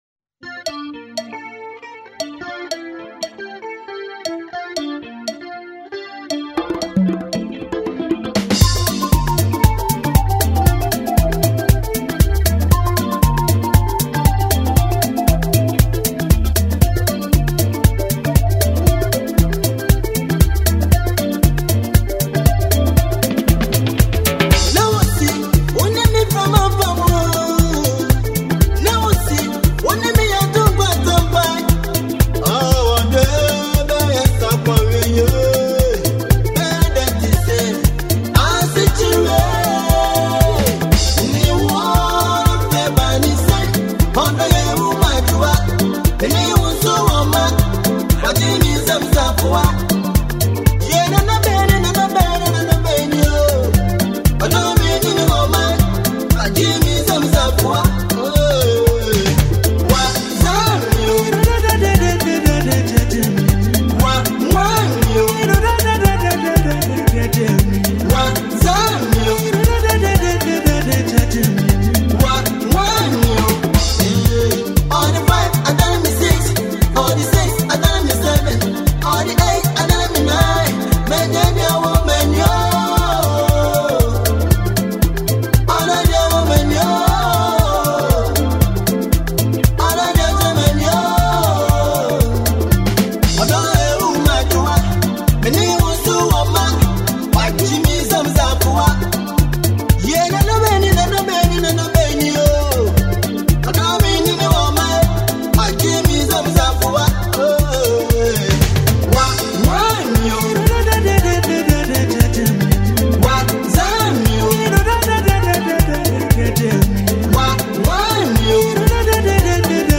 classic hi life tune